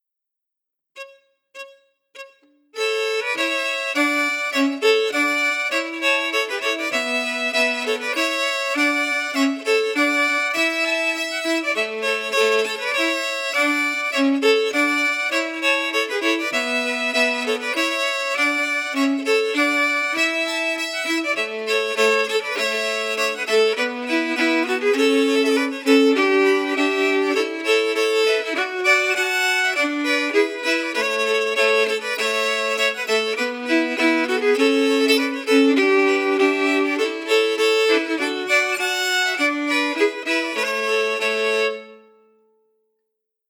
Key: A
Form: March
Harmony emphasis
Genre/Style: Scottish (pipe) March